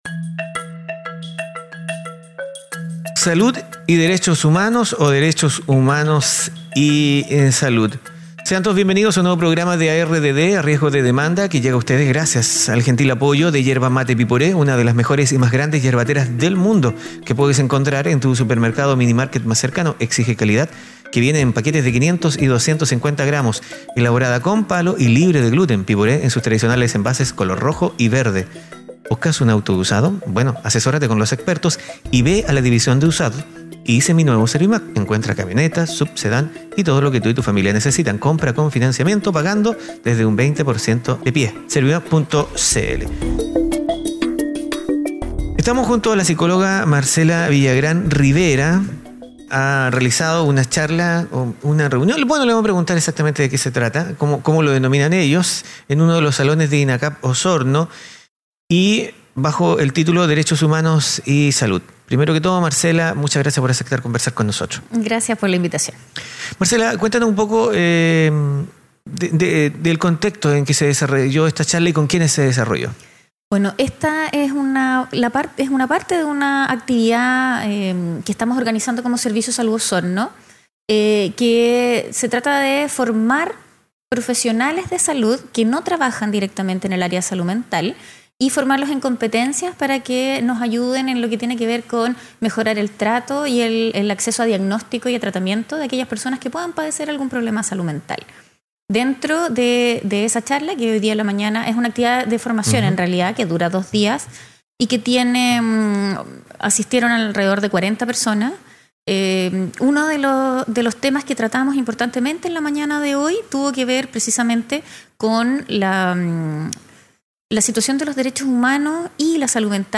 terminada esa instancia conversamos con ella: